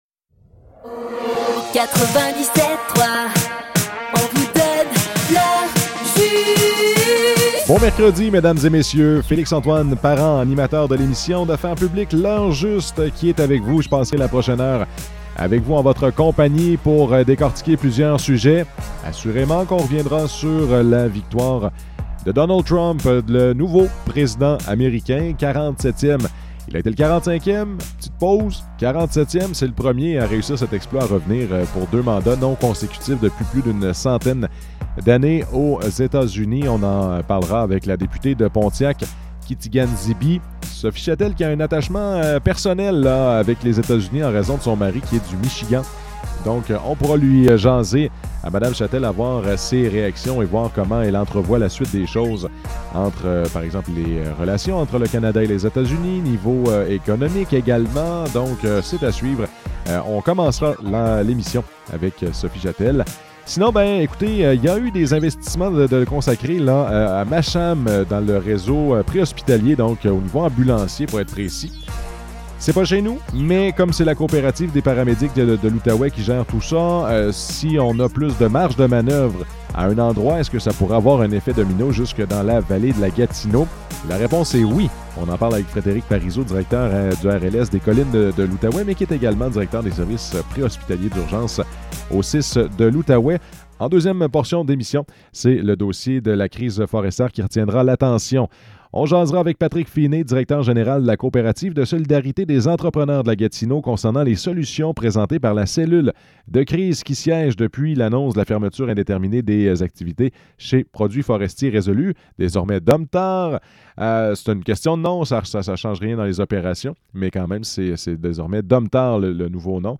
Entrevue avec Sophie Chatel